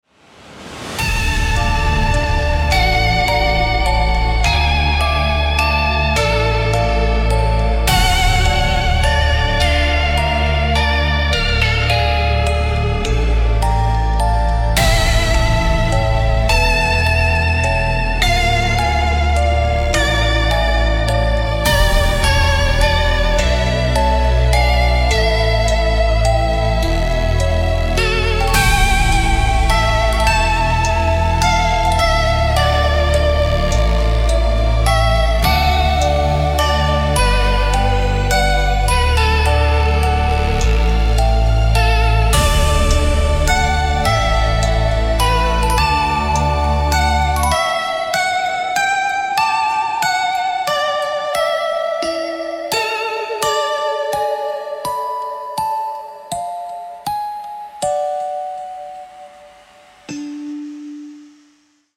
Scary Music Box Halloween Music Box Horror Creepy